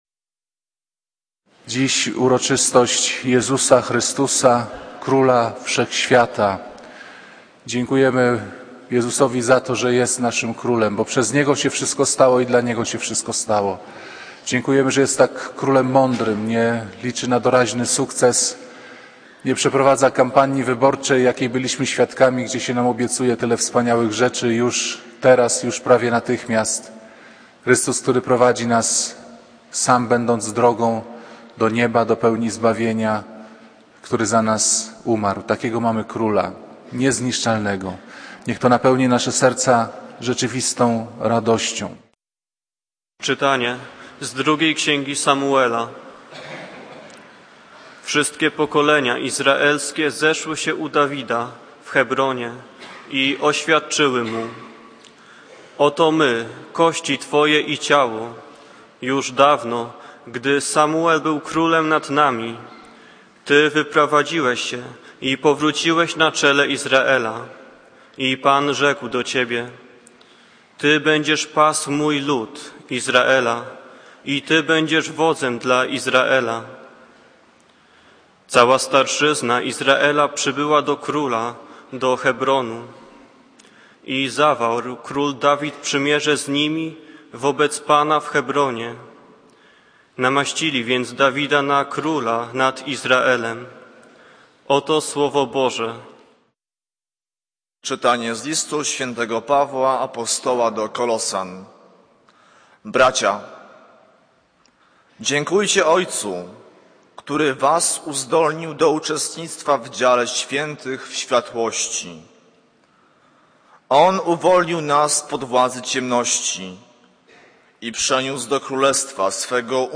Kazanie z 21 listopada 2010r.